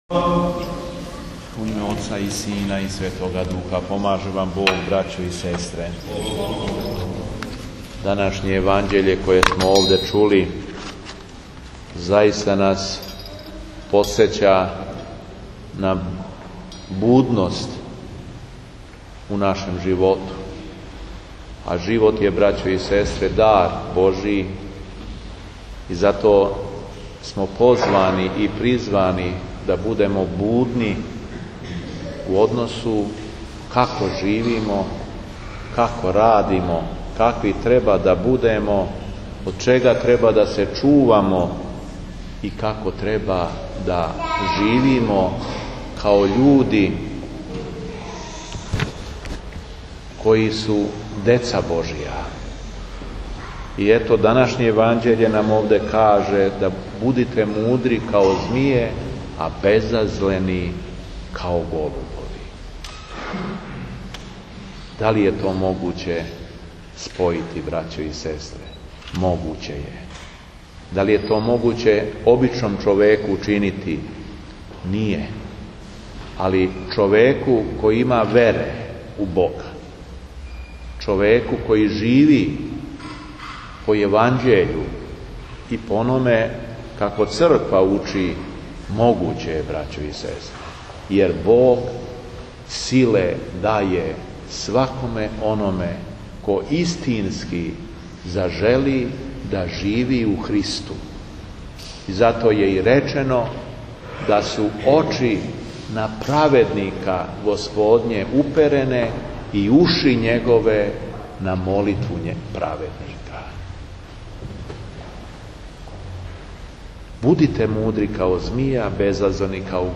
Беседа епископа шумадијског Г. Јована у Барајеву
Владика је после прочитаног јеванђеља подсетио верни народ на будност у животу у односу како живимо и како треба да радимо као људи који су деца Божија.